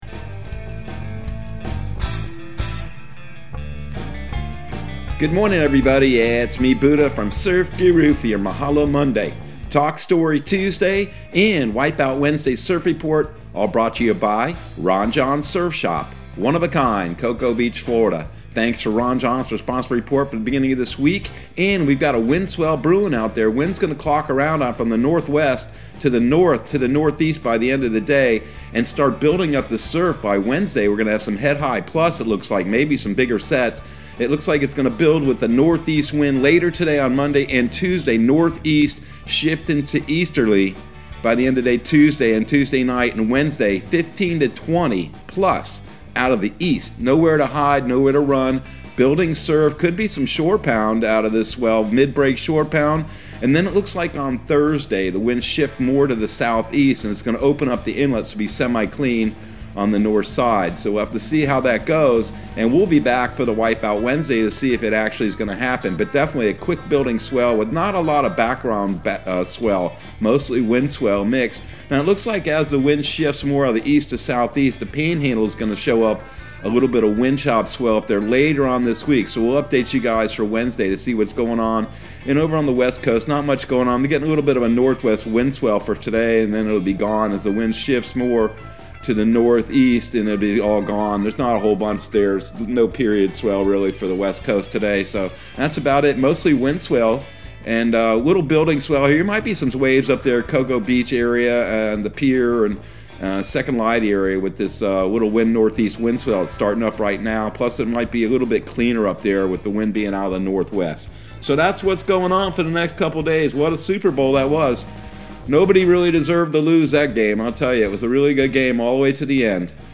Surf Guru Surf Report and Forecast 02/14/2022 Audio surf report and surf forecast on February 14 for Central Florida and the Southeast.